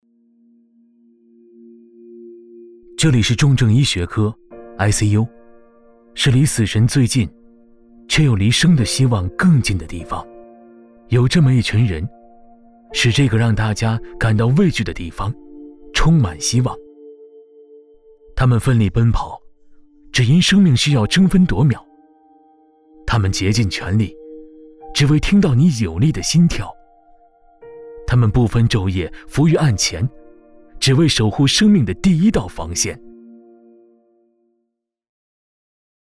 旁白-男19-ICUx.mp3